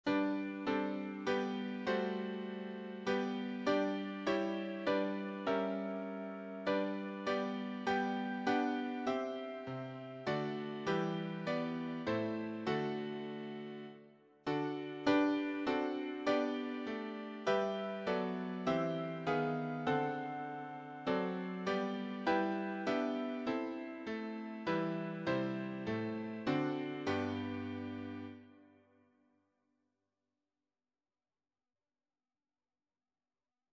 choir SATB
Sacred choral songs